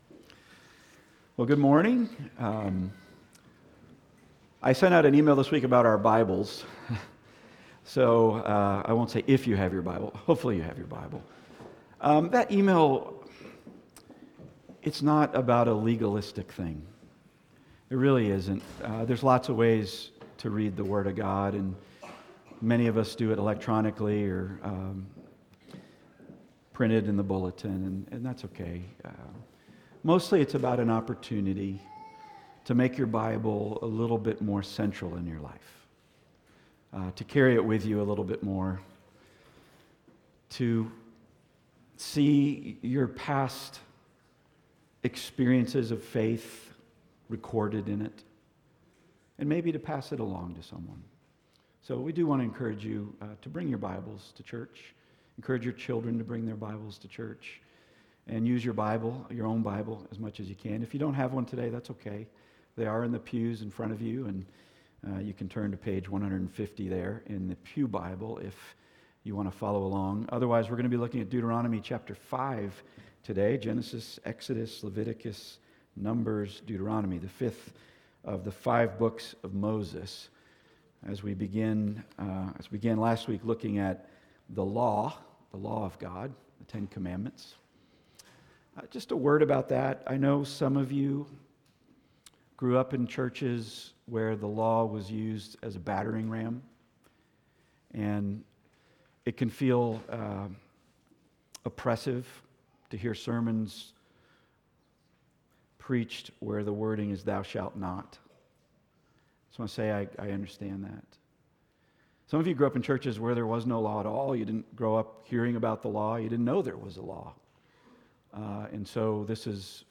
Passage: Deuteronomy 5:8-10 Service Type: Weekly Sunday